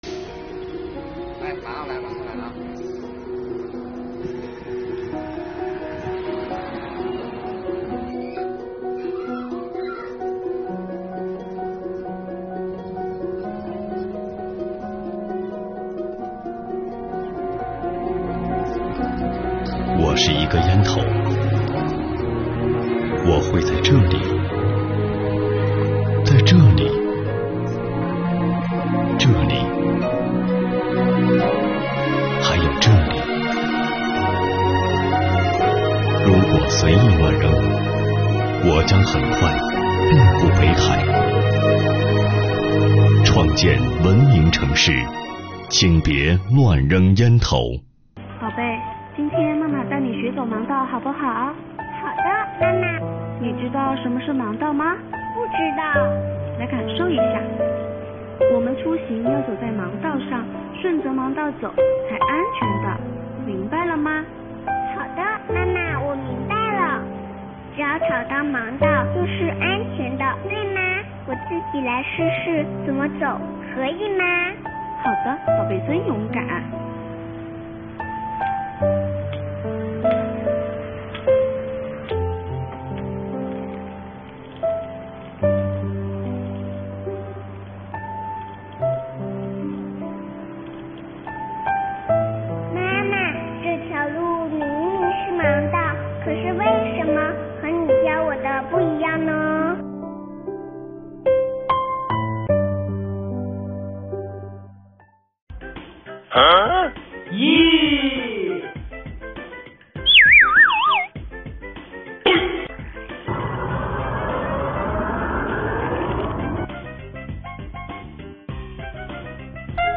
由北海市委网信办、北海市创城办指导，北海市网络文化协会制作的创城短视频，以轻松活泼、诙谐幽默的手法，展现了乱扔烟头、占用盲道、不礼让斑马线等不文明行为带来的危害和不良影响，也提醒我们每一位市民：做文明市民，从我做起，从小事做起！